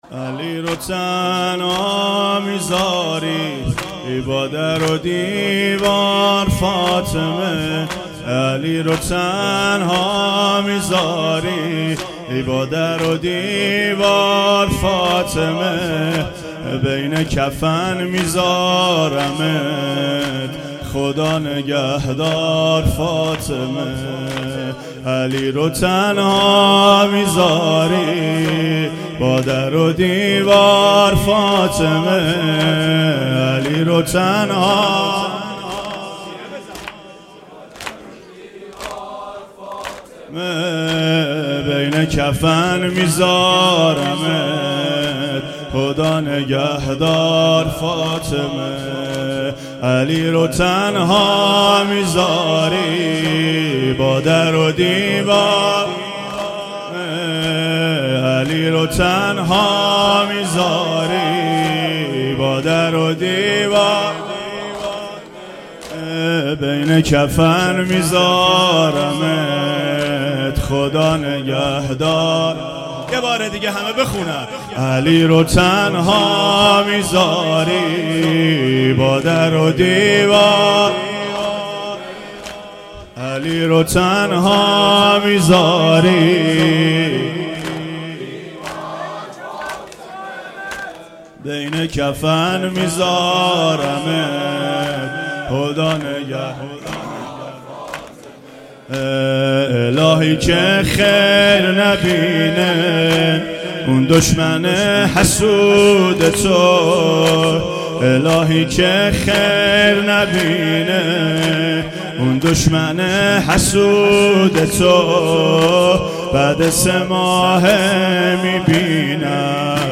زمینه شب پنجم فاطمیه